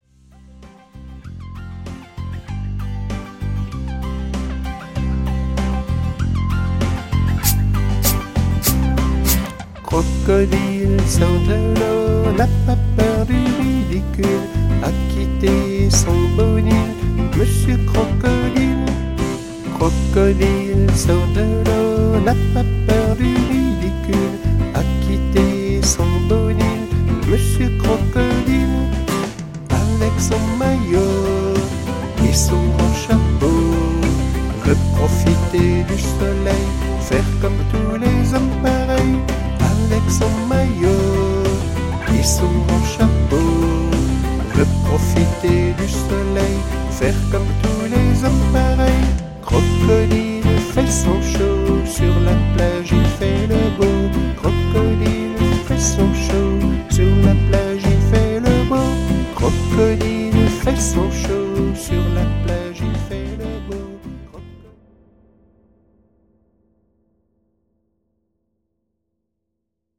version vocale GS-CP-CE1